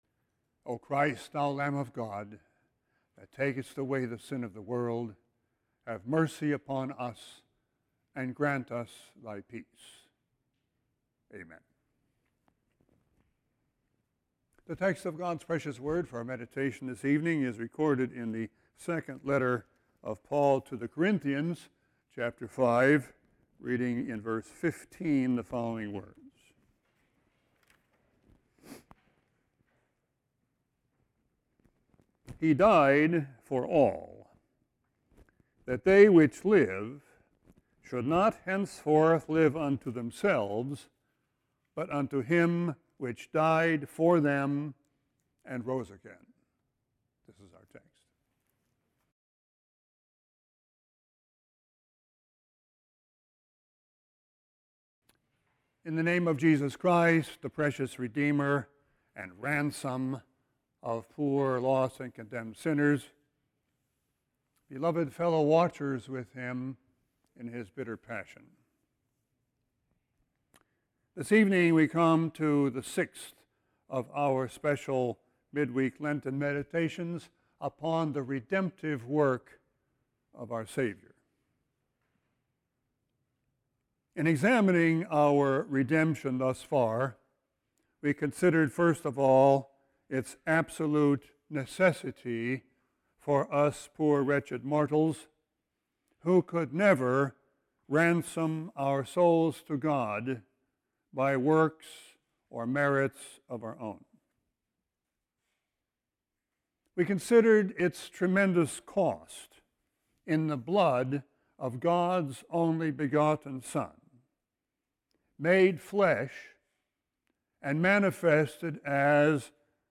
Sermon 3-21-18.mp3